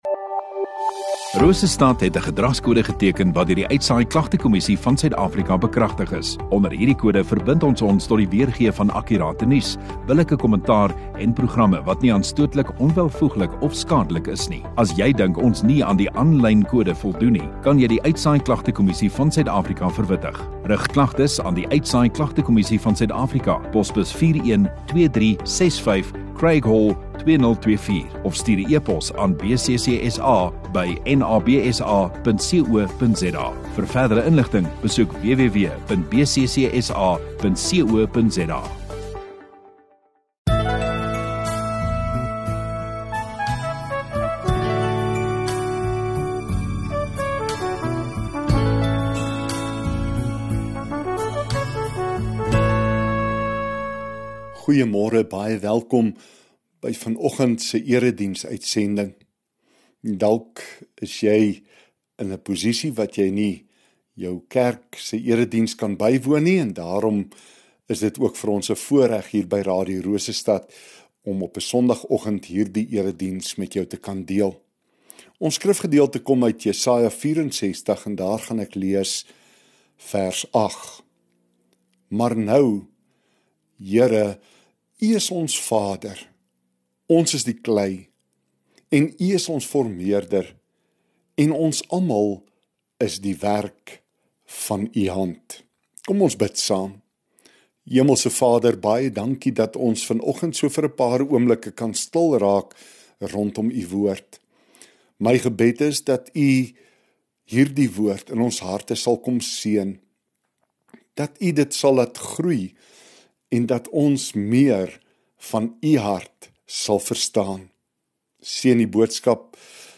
22 Jun Sondagoggend Erediens